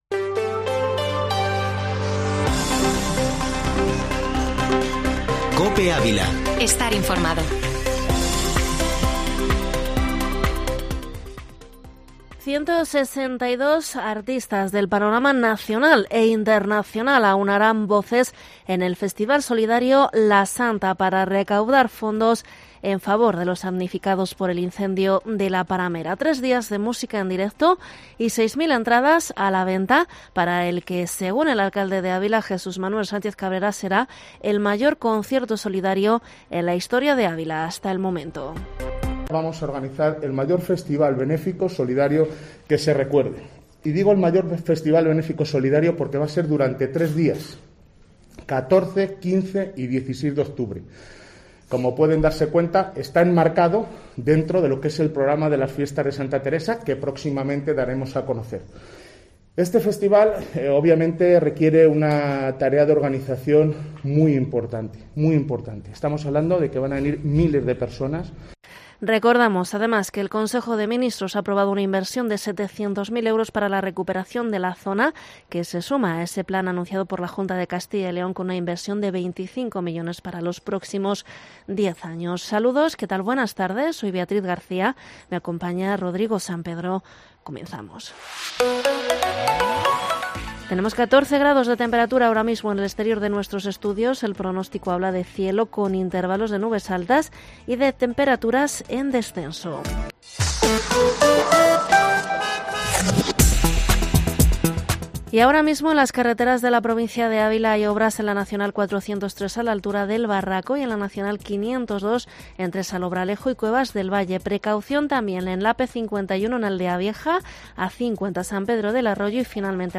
informativo Mediodía COPE ÁVILA 29/09/2021